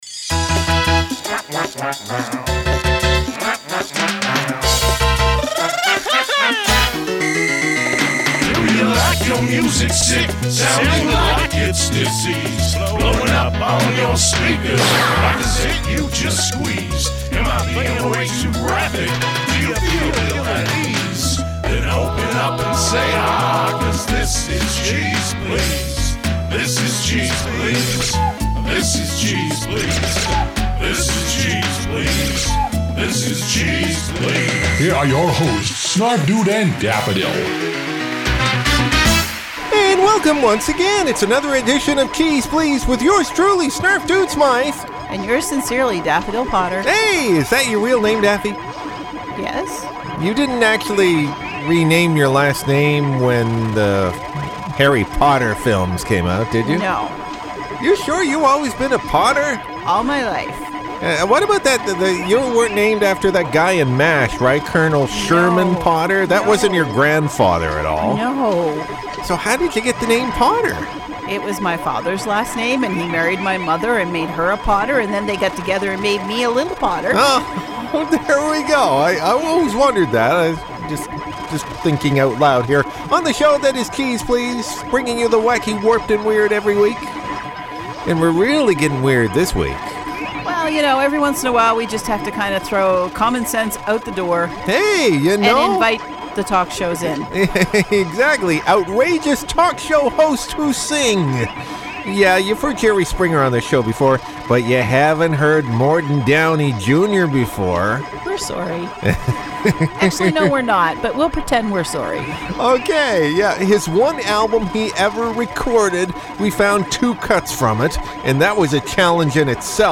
The golden throats of outrageous TV talk become painful to the ear when they sing...